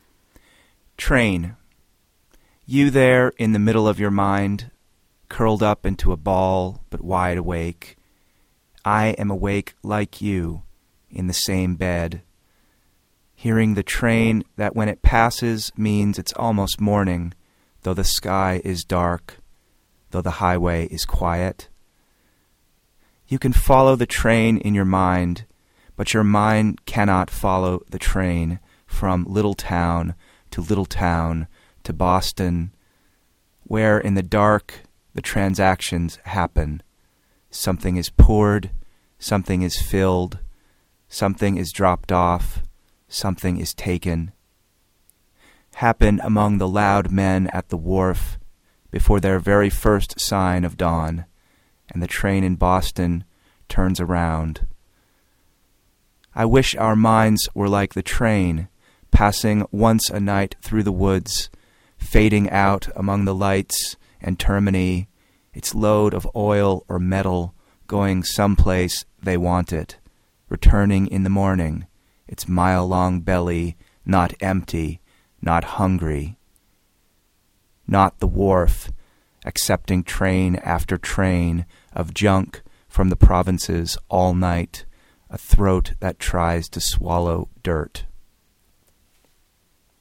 poem, mp3) 1:30